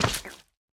Minecraft Version Minecraft Version snapshot Latest Release | Latest Snapshot snapshot / assets / minecraft / sounds / block / honeyblock / step5.ogg Compare With Compare With Latest Release | Latest Snapshot